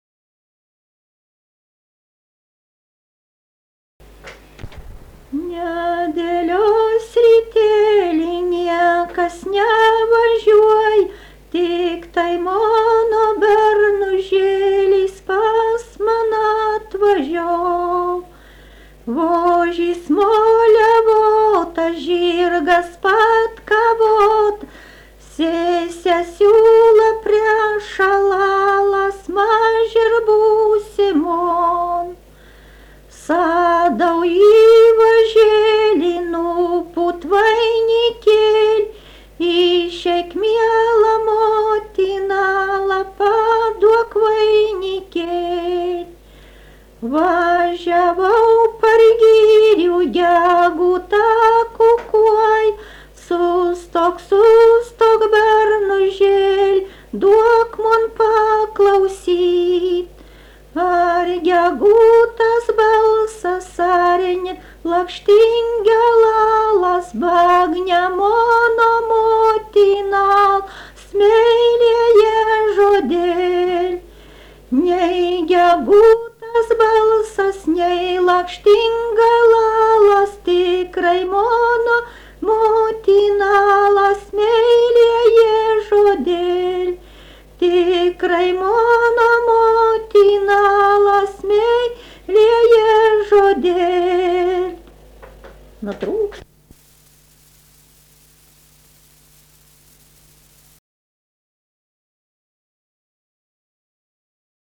daina, vestuvių
Erdvinė aprėptis Šimonys
Atlikimo pubūdis vokalinis